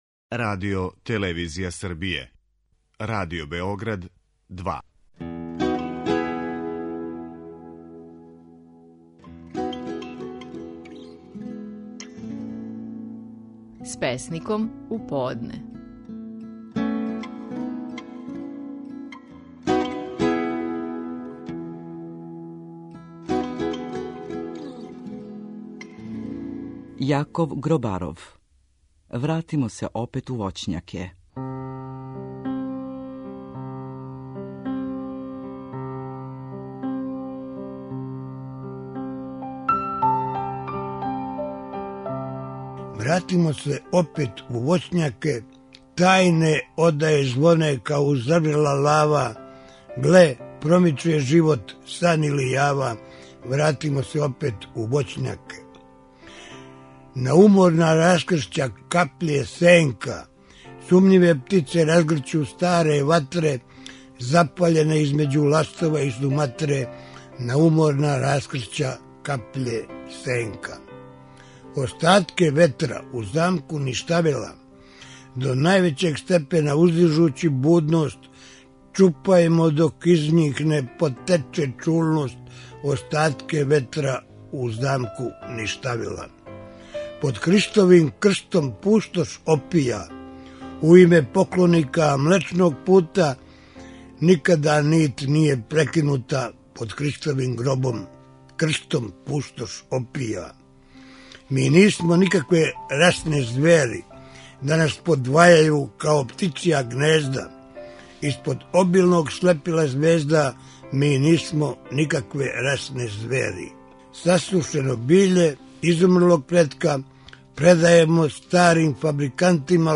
Стихови наших најпознатијих песника, у интерпретацији аутора.
Можете чути како је  стихове своје песме под називом „Вратимо се опет у воћњаке" говорио Јаков Гробаров.